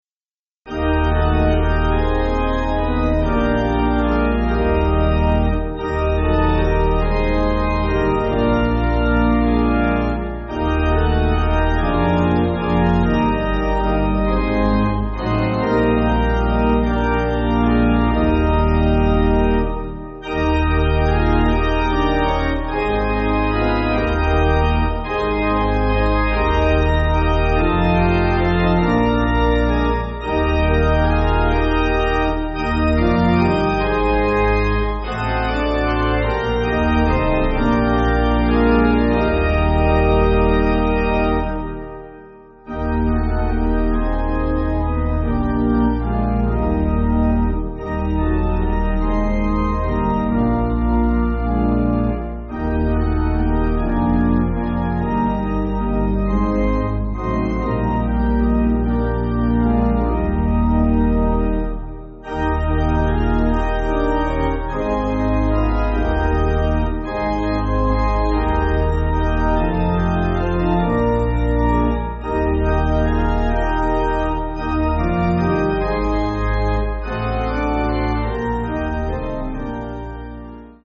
Organ
(CM)   4/Eb